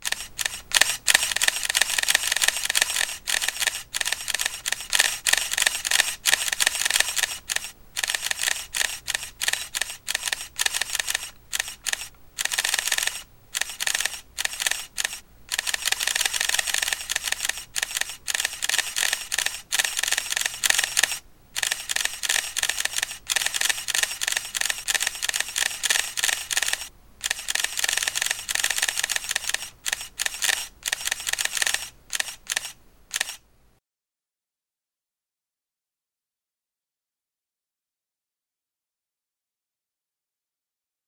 Motion sensor triggering of the flashes and the sound as guests walk by!